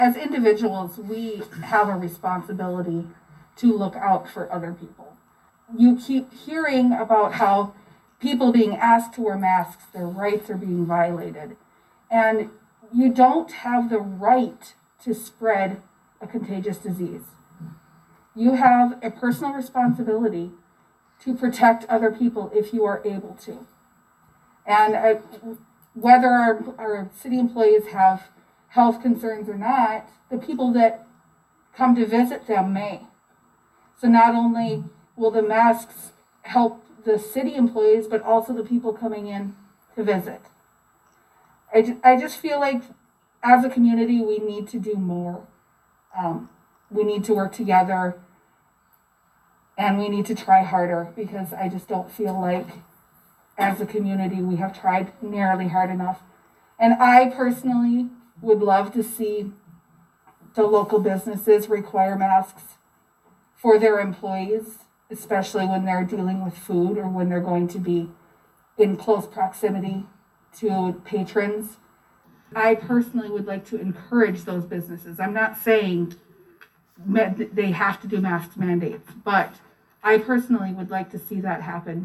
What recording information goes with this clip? Mobridge City Council discusses mask policies